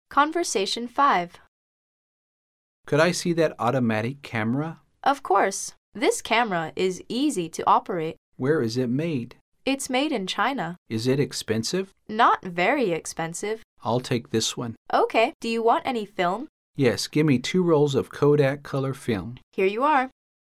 旅游英语口语情景对话大全：旅游摄影 5(mp3下载+lrc)
Conversation 5